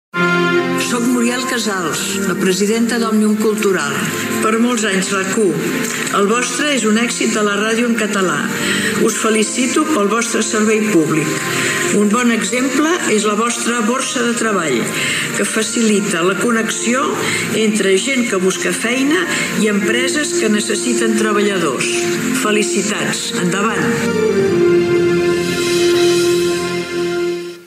Felicitacions pels 15 anys de RAC 1.
De la presidenta d``Omnium Cultural Muriel Casals.